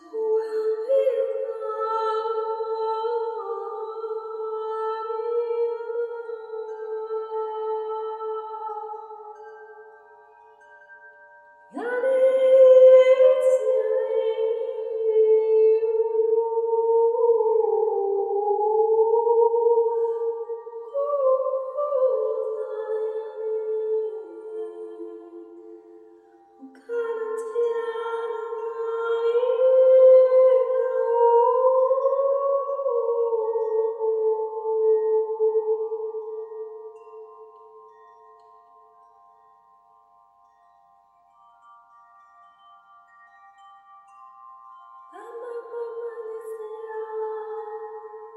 Carillons et Voix                    Durée 07:50
Quelques précieuses minutes de douceur et de plénitude